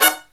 HIGH HIT14-R.wav